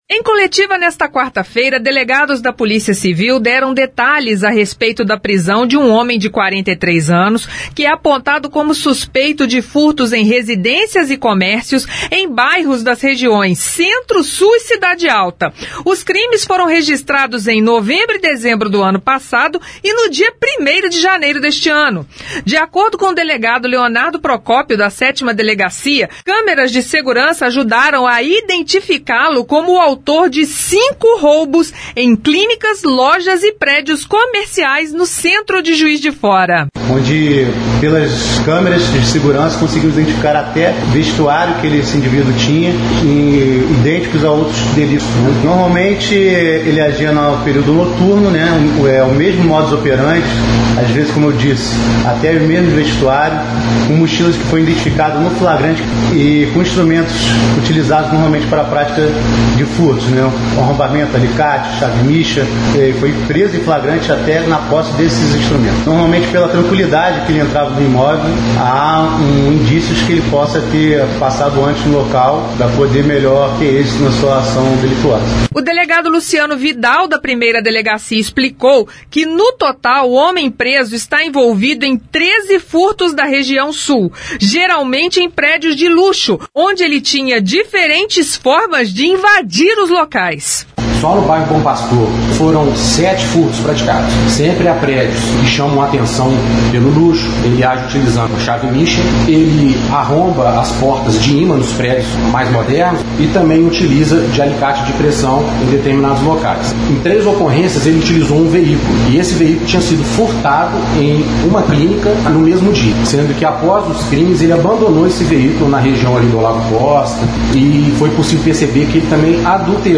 Em coletiva, delegados da Polícia Civil deram detalhes sobre a forma como ele agia e os próximos passos investigação